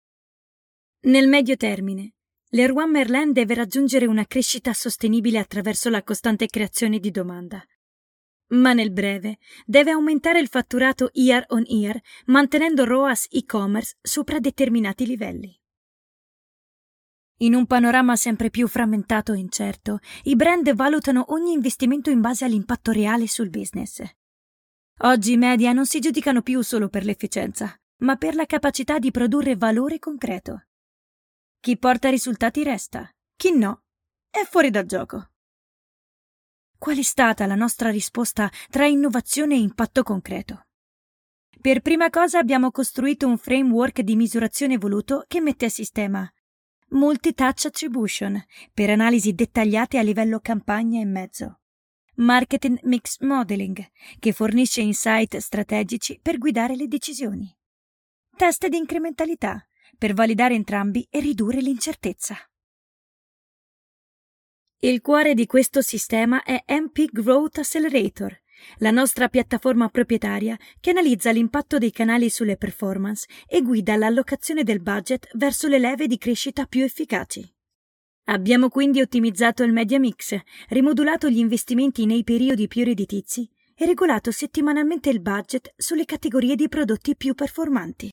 audioguida
caratterizzazioni varie da bambini, ragazzini a donne